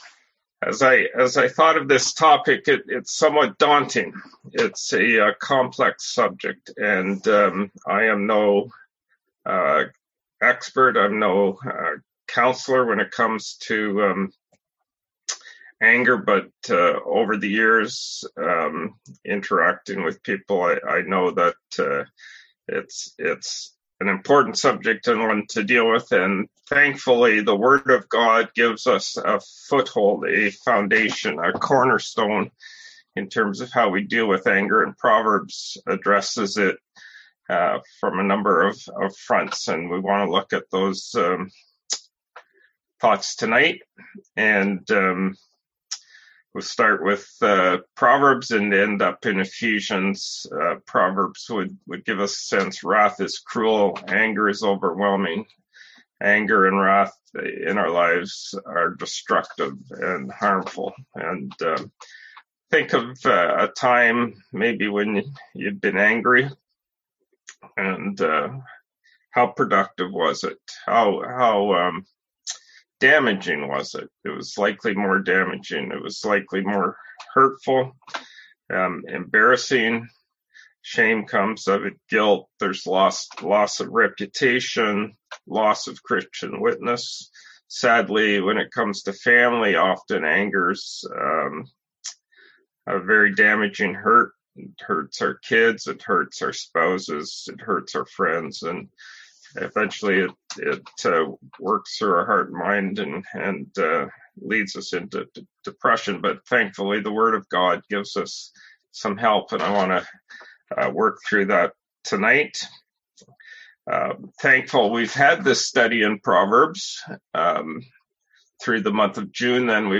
Service Type: Seminar Topics: Anger , Self-control